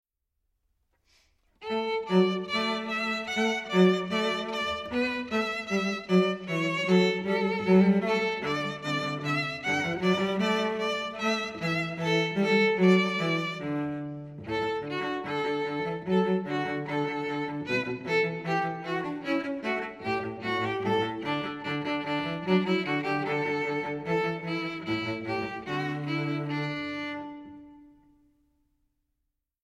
Voicing: String Bass Duet